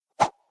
woosh2.ogg